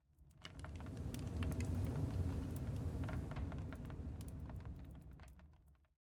blastfurnace3.ogg